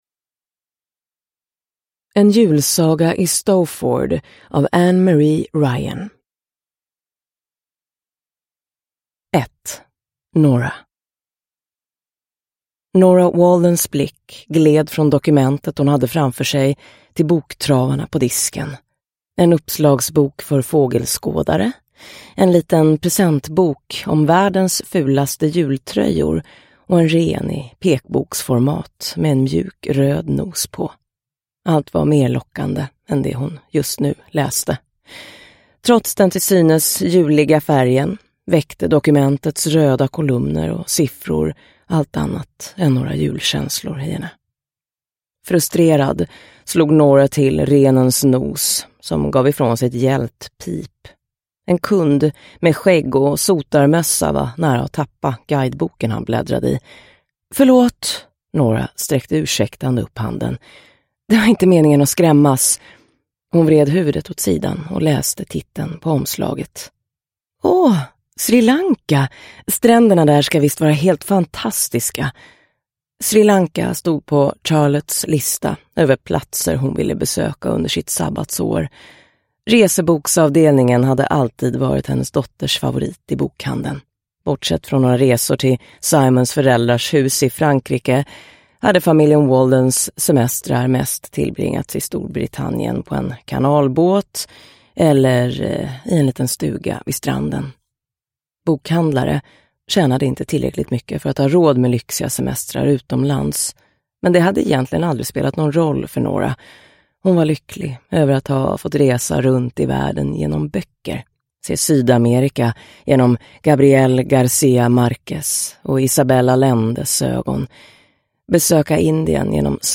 En julsaga i Stowford – Ljudbok – Laddas ner